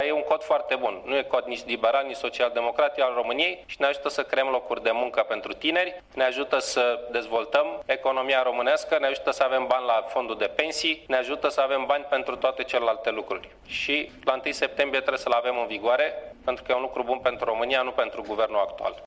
El a mai declarat că îşi doreşte rezolvarea problemei noului Cod Fiscal până la 1 septembrie, printr-o sesiune extraordinară a Parlamentului, aşa cum s-a stabilit în coaliţia guvernamentală :